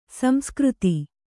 ♪ samskřti